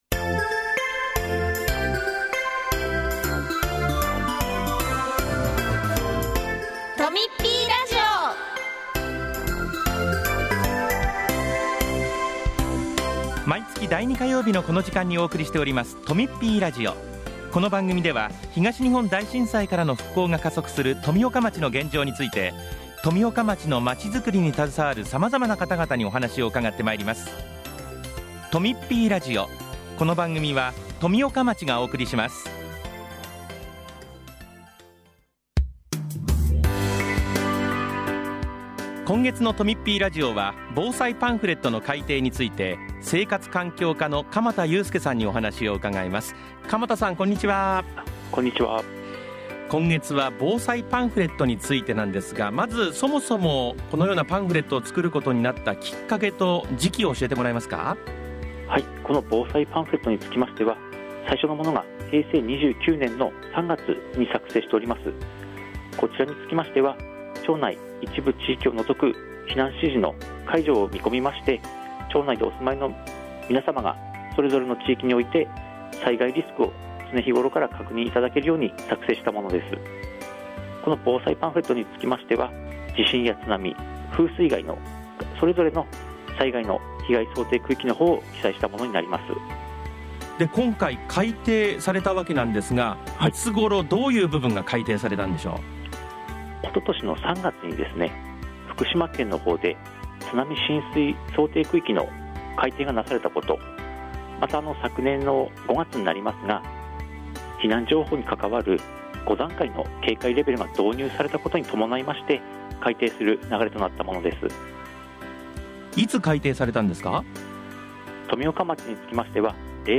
5月12日（火曜日）に放送した「とみっぴーラジオ」を、お聴きいただけます。